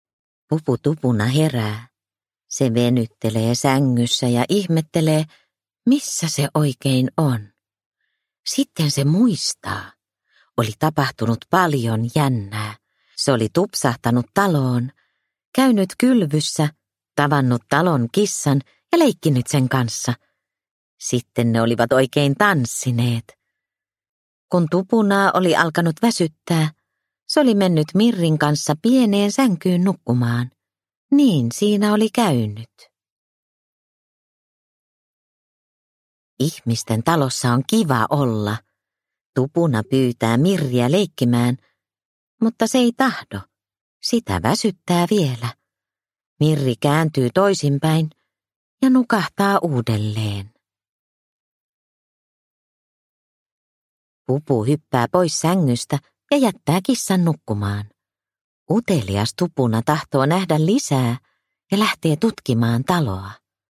Utelias Pupu Tupuna – Ljudbok – Laddas ner